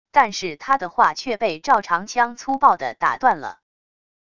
但是他的话却被赵长枪粗暴的打断了wav音频生成系统WAV Audio Player